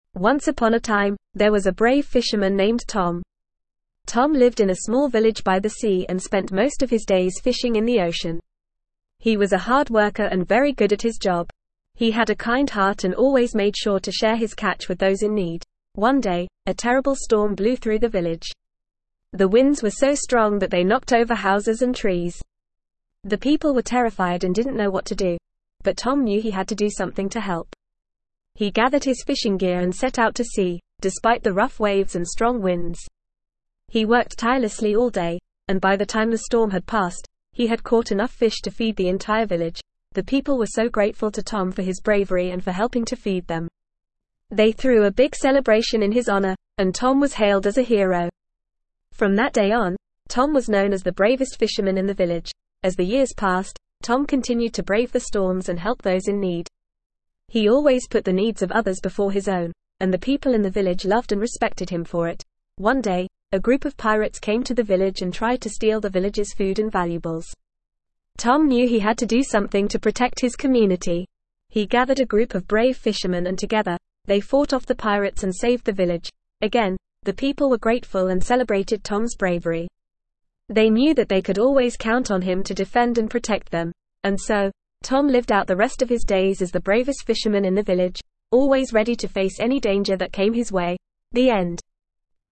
Normal
ESL-Short-Stories-for-Kids-NORMAL-reading-Tom-the-Brave-Fisherman.mp3